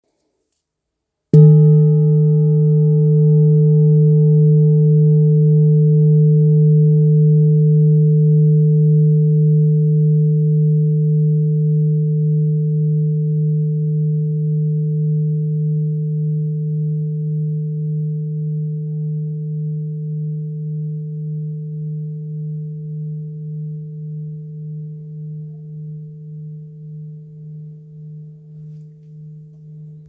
Kopre Singing Bowl, Buddhist Hand Beaten, Antique Finishing
Material Seven Bronze Metal
It is accessible both in high tone and low tone .